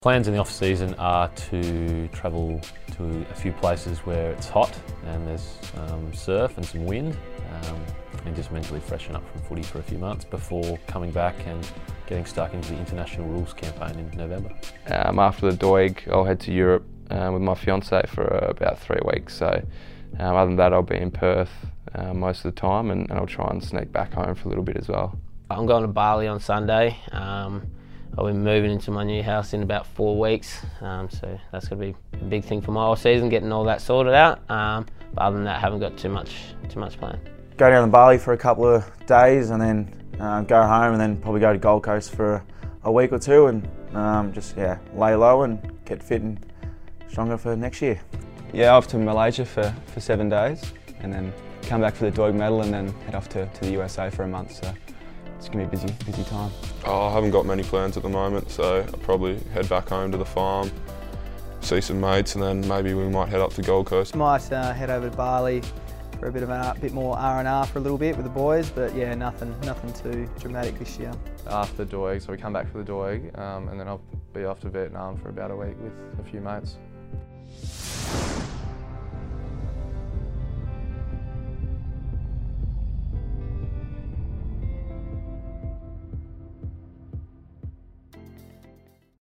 We ask the playing group about their off-season plans.